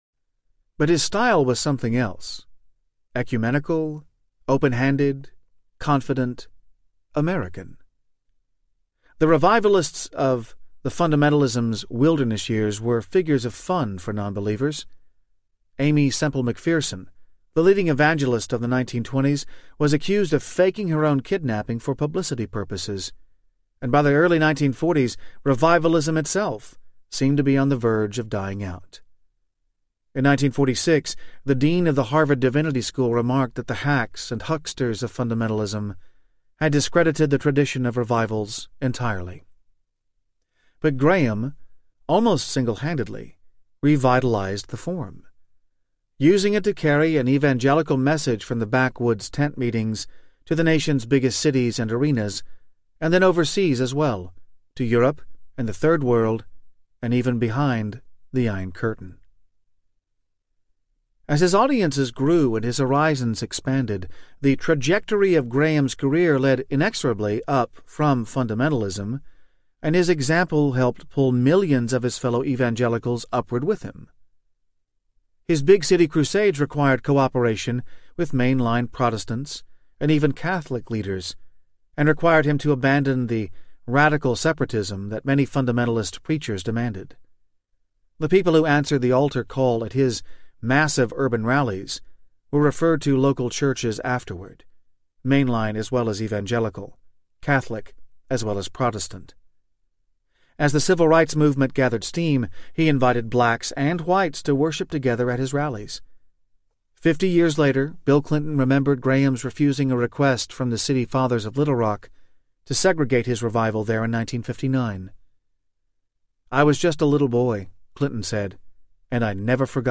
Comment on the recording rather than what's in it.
13.0 Hrs. – Unabridged